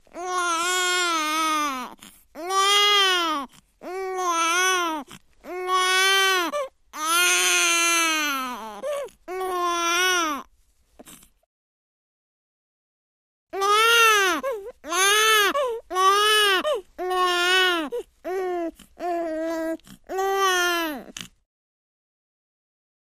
Newborn Baby Wail x2